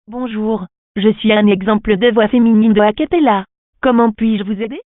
Example of the voices available in our catalog
voix-acapela2.wav